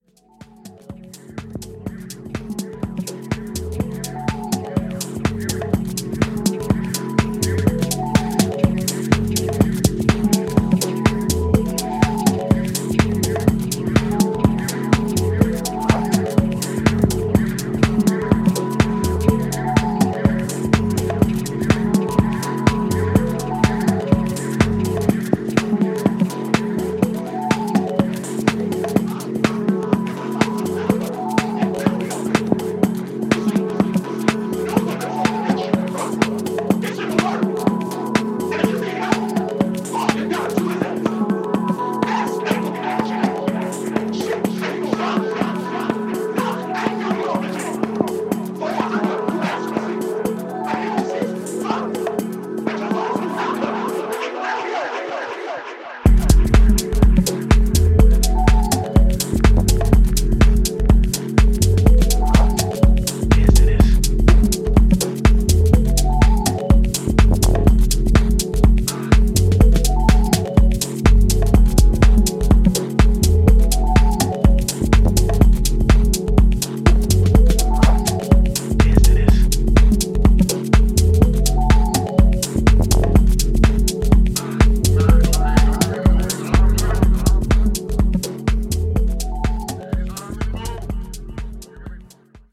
Groovy, Deep Minimal Tracks On Each Side.